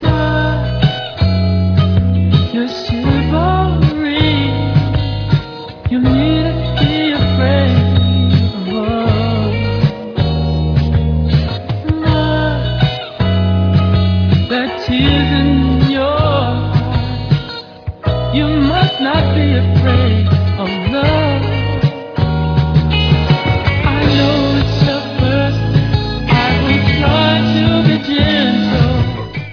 lead and background vocals, and guitar